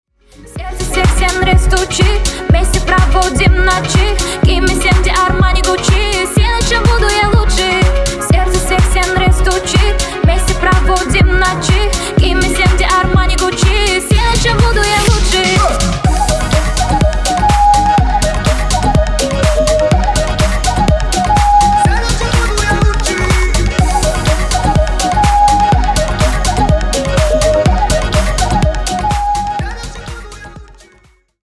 Ремикс # Казахские # ритмичные